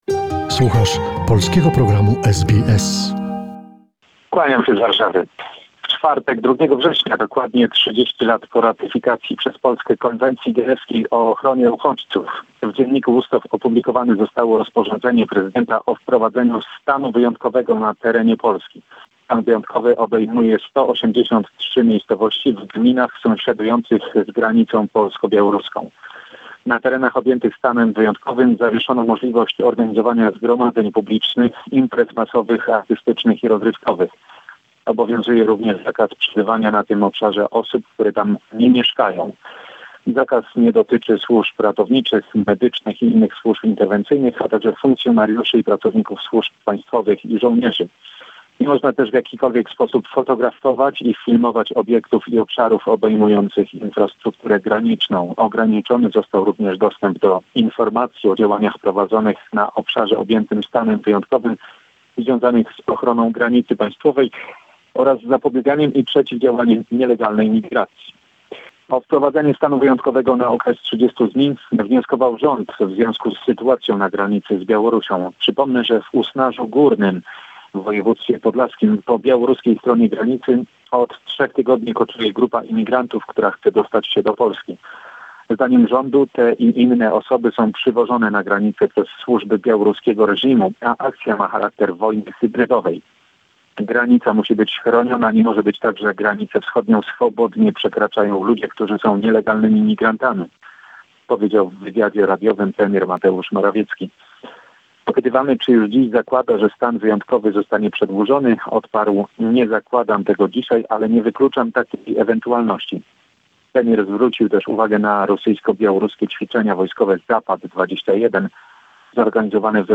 Summary of the most important events of last week in Poland. Report